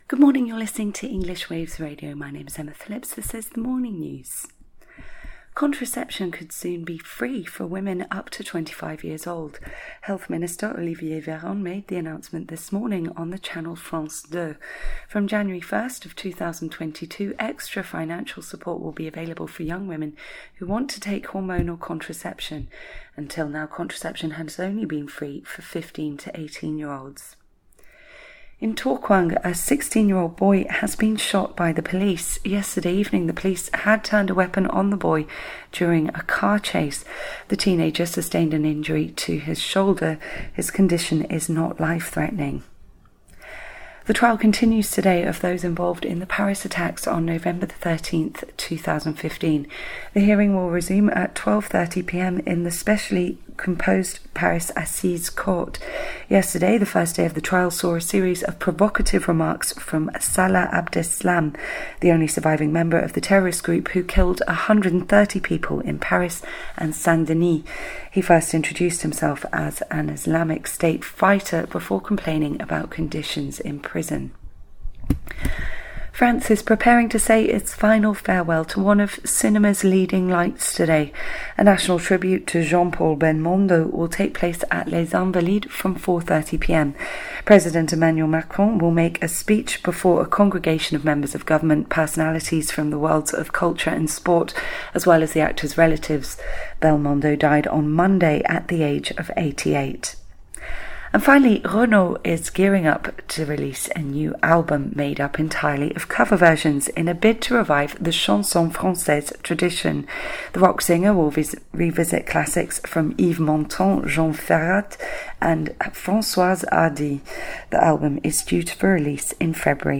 Listen to today’s news In France in English!